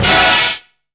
ENGINE STAB.wav